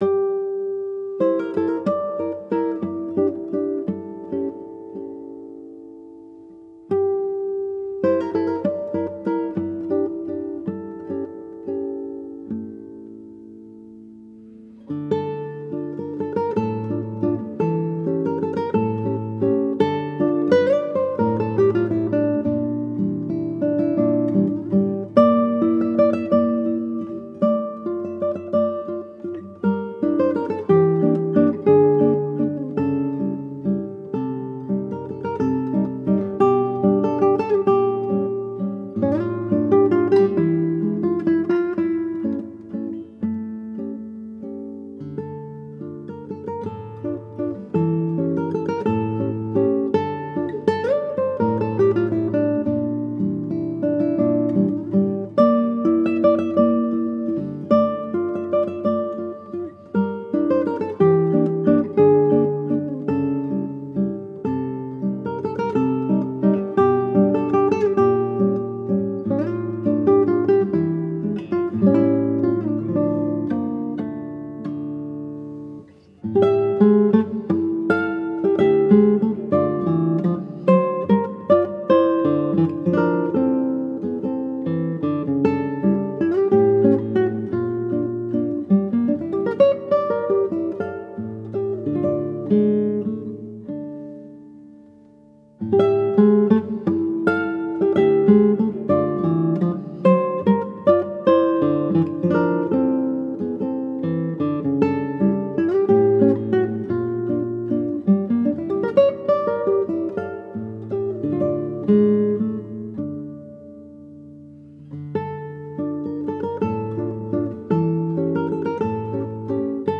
gitariste